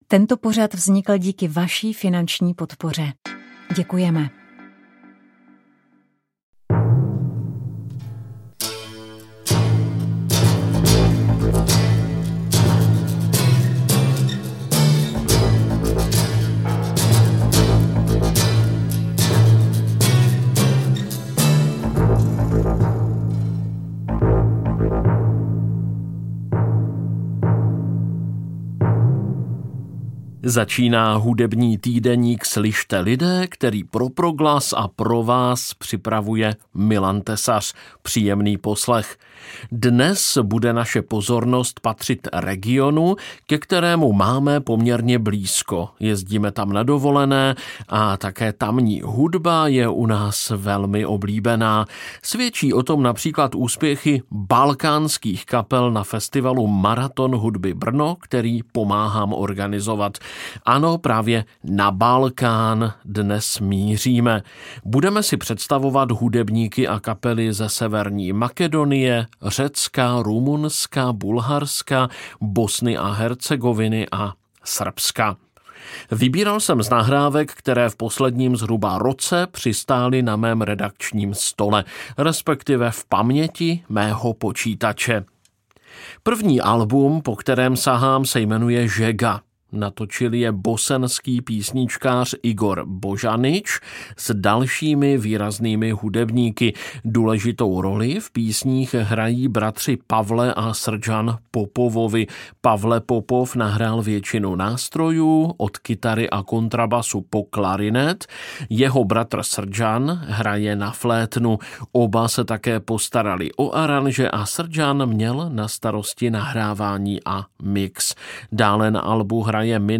V týdeníku Slyšte, lidé! se vydáme na hudební cestu po Balkánu – od Bosny a Hercegoviny přes Severní Makedonii a Rumunsko až po Řecko a Bulharsko. Představíme si výběr nových nahrávek, v nichž se tradiční lidová hudba prolíná s jazzem, elektronikou, autorskou tvorbou i experimentálními fúzemi. Uslyšíte jak autentický folklor, tak moderní projekty přinášející Balkán v překvapivých, svěžích podobách.